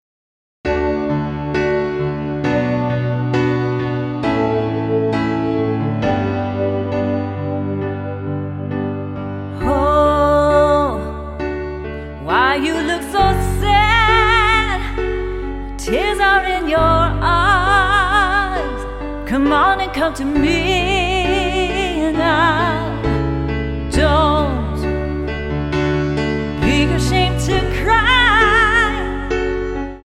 Tonart:D Multifile (kein Sofortdownload.
Die besten Playbacks Instrumentals und Karaoke Versionen .